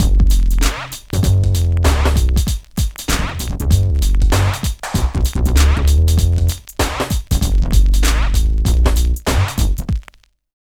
112 LOOP  -L.wav